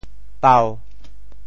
“苞”字用潮州话怎么说？